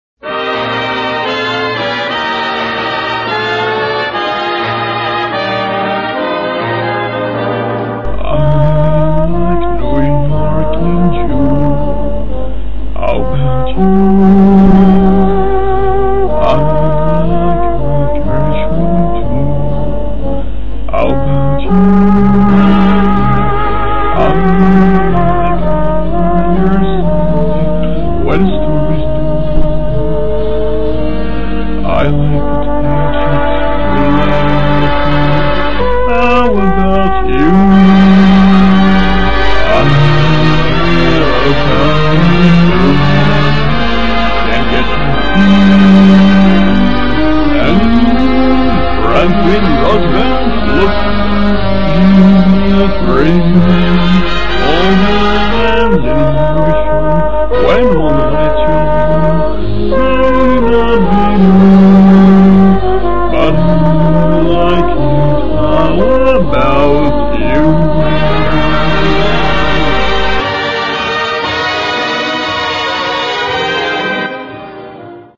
I love singing even though I stink at it.